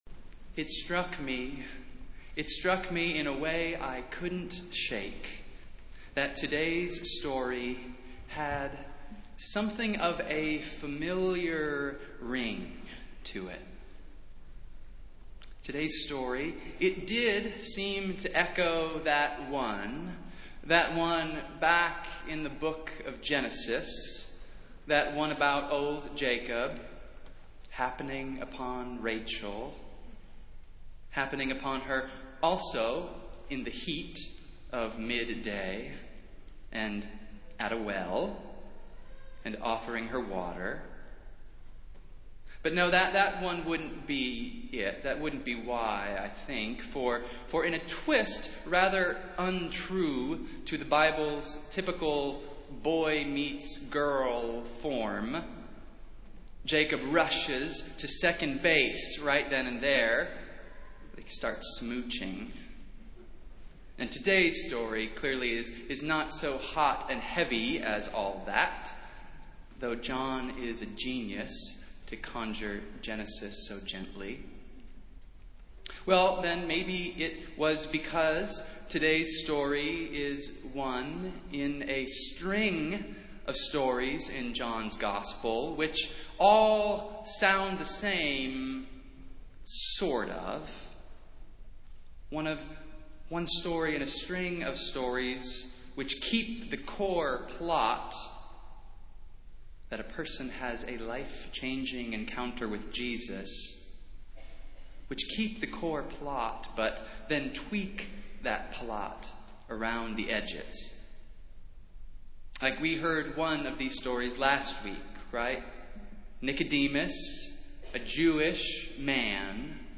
Festival Worship - Third Sunday in Lent